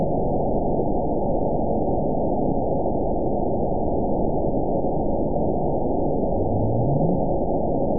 event 911794 date 03/09/22 time 01:31:03 GMT (3 years, 3 months ago) score 9.71 location TSS-AB01 detected by nrw target species NRW annotations +NRW Spectrogram: Frequency (kHz) vs. Time (s) audio not available .wav